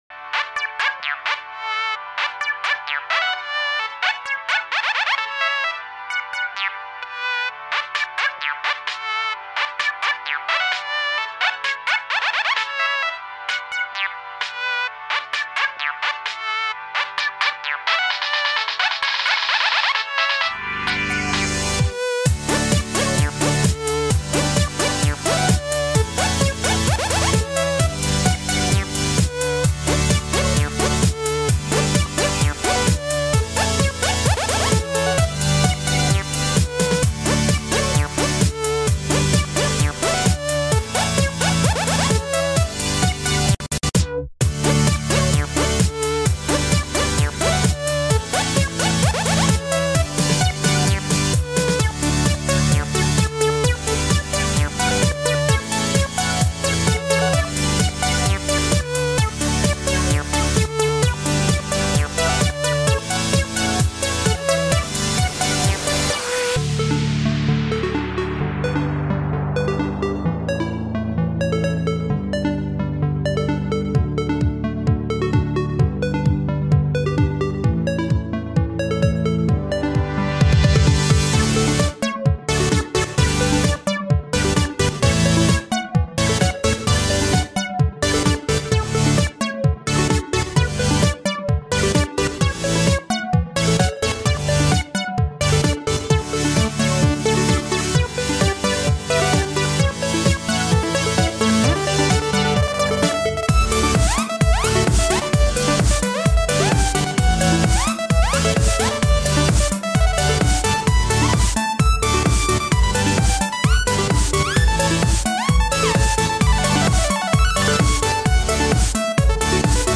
genre:electro house